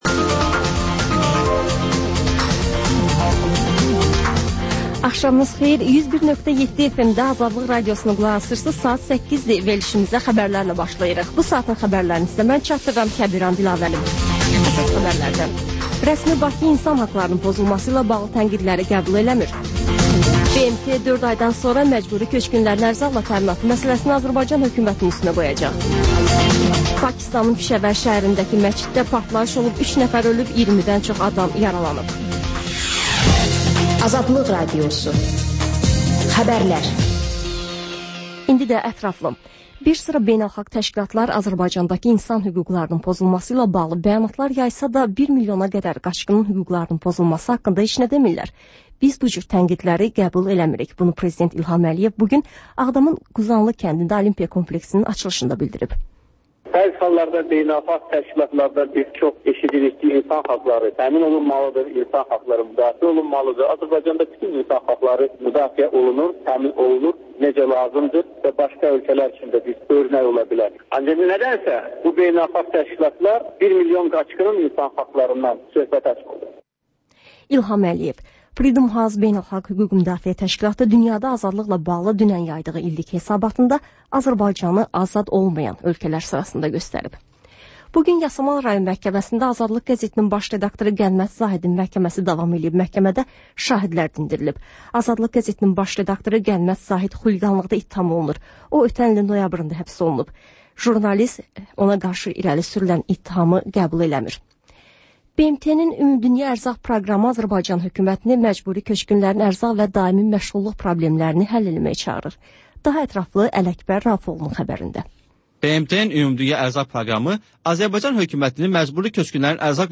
Xəbərlər, müsahibələr, hadisələrin müzakirəsi, təhlillər, sonra QAFQAZ QOVŞAĞI rubrikası: «Azadlıq» Radiosunun Azərbaycan, Ermənistan və Gürcüstan redaksiyalarının müştərək layihəsi